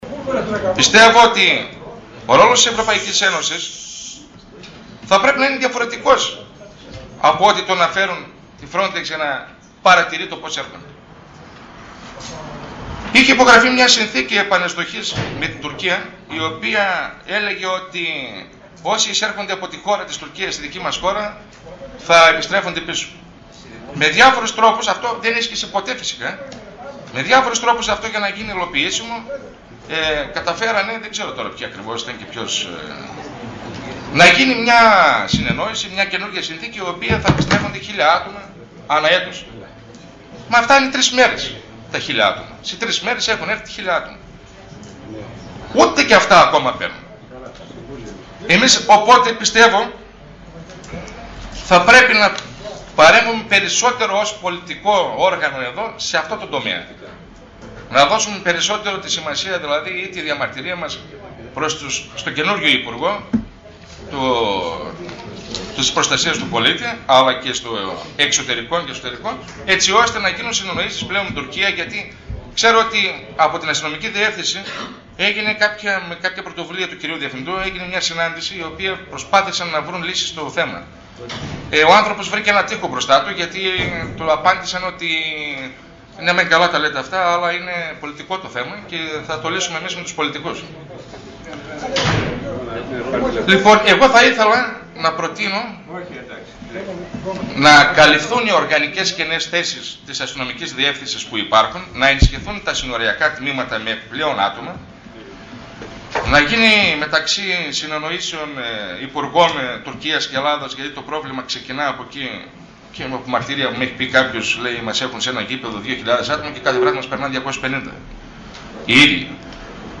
Αντιδήμαρχος Α. Ουρουμίδης: Τι πρέπει να γίνει για το ζήτημα της λαθρομετανάστευσης – Δημ. Συμβ. Ορ/δας 14.09.2010